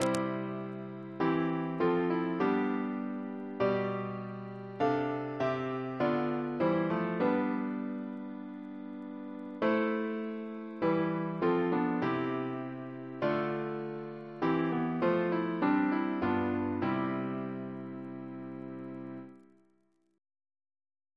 Double chant in C Composer: Walter Biery (b.1958)